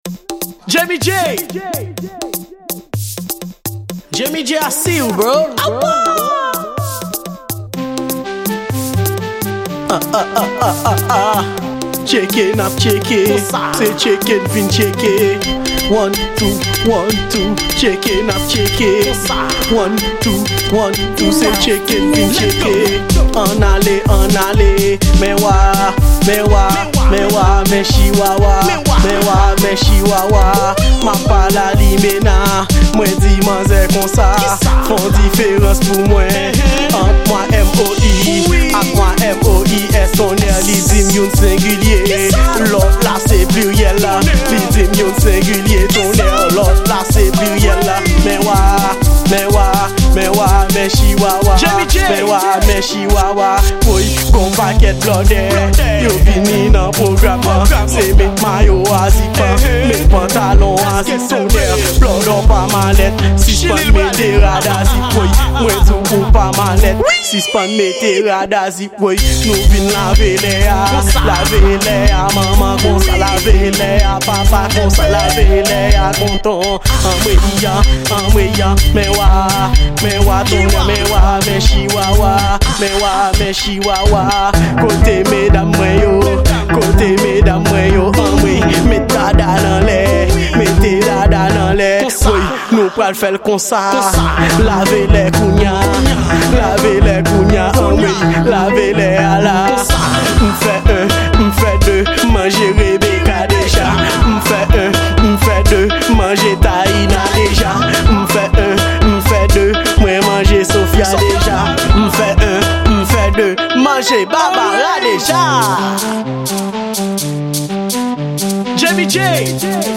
Genre: Raboday